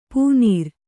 ♪ pūnīr